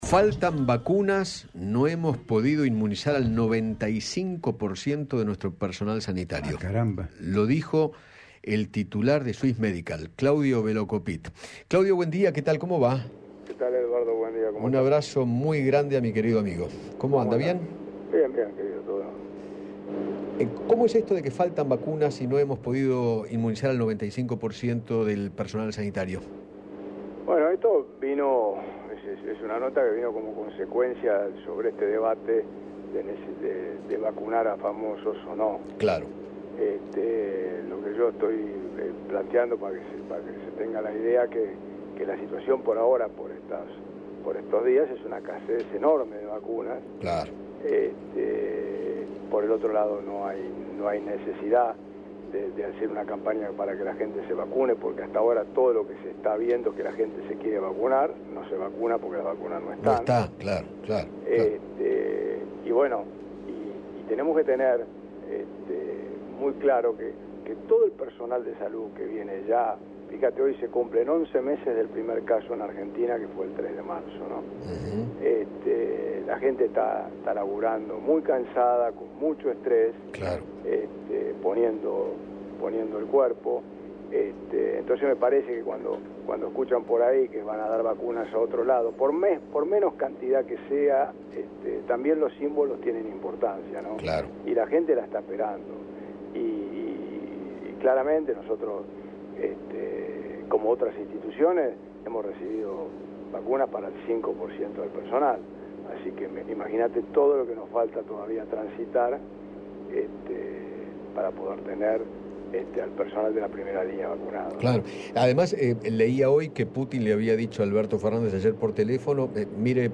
Claudio Belocopitt, titular de Swiss Medical, dialogó con Eduardo Feinmann acerca de la escasez de vacunas y la falta de organización para aplicar las dosis tanto a los empleados de salud como a las personas de riesgo.